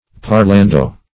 Meaning of parlando. parlando synonyms, pronunciation, spelling and more from Free Dictionary.
Search Result for " parlando" : The Collaborative International Dictionary of English v.0.48: Parlando \Par*lan"do\, Parlante \Par*lan"te\, a. & adv.